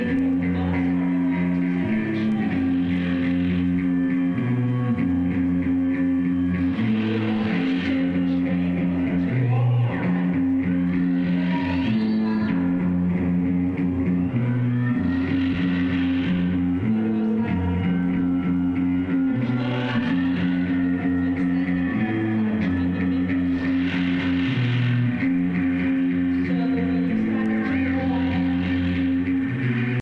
guitarras, baixo, percussões